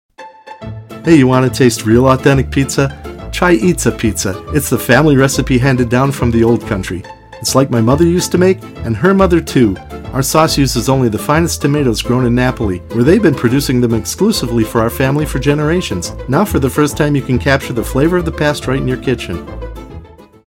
• Commercials
With a tone that’s clear, friendly and easy to listen to, I help brands share their stories, reach their audiences, and create personal experiences.
Eastsa-Pizza_with-Music.mp3